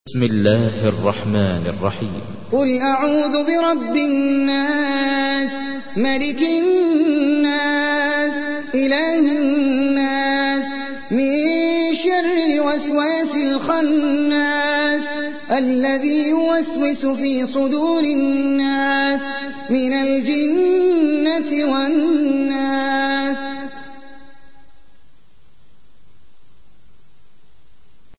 تحميل : 114. سورة الناس / القارئ احمد العجمي / القرآن الكريم / موقع يا حسين